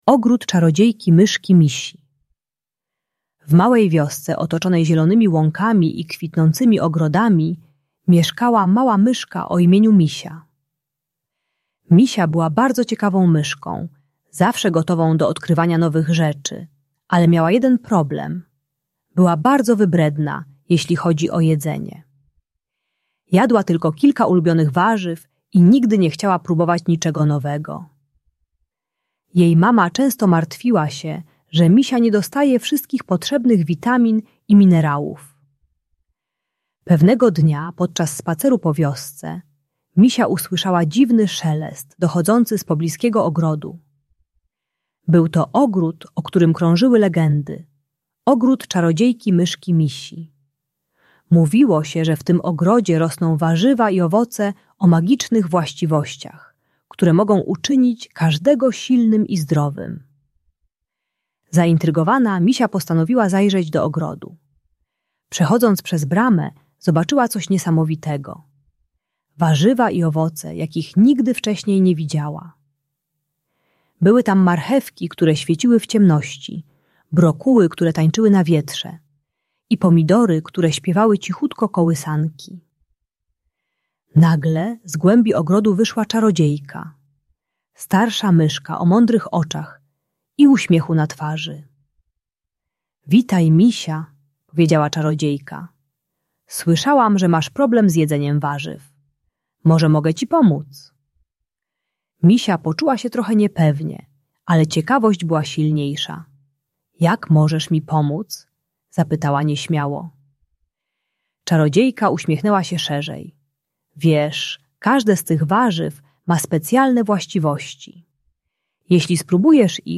Ogród Czarodziejki Myszki Misi - Problemy z jedzeniem | Audiobajka